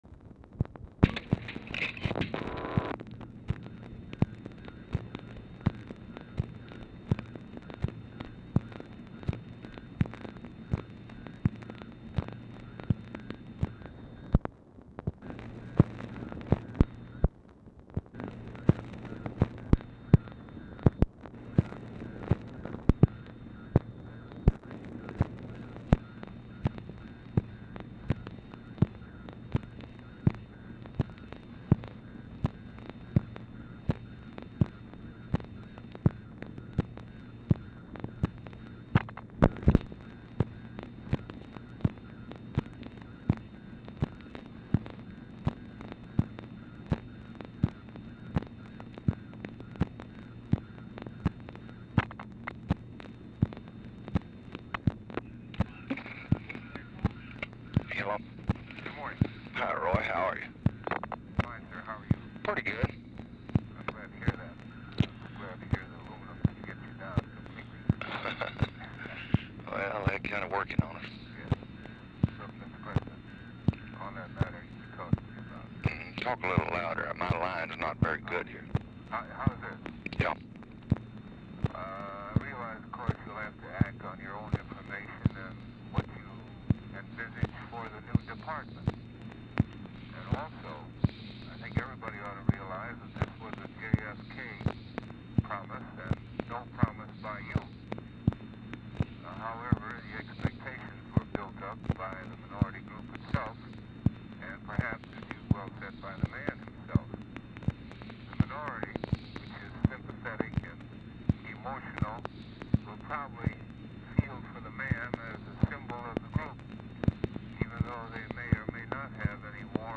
Telephone conversation # 9101, sound recording, LBJ and ROY WILKINS
WILKINS ON HOLD 1:00; CALL IS DISCONNECTED, THEN RECONNECTED BUT RECORDING ENDS BEFORE CONVERSATION BETWEEN WILKINS AND LBJ RESUMES
Format Dictation belt
LBJ Ranch, near Stonewall, Texas
Other Speaker(s) TELEPHONE OPERATOR